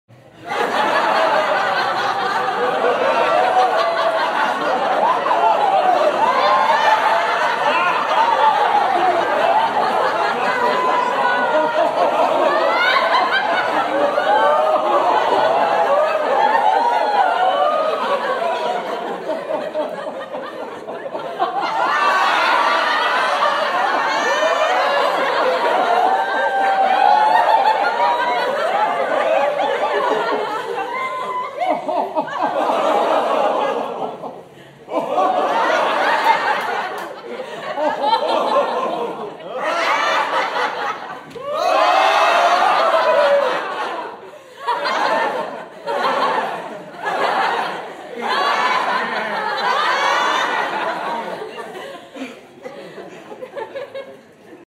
جلوه های صوتی
دانلود صدای خندیدن جمعیت و مردم 3 از ساعد نیوز با لینک مستقیم و کیفیت بالا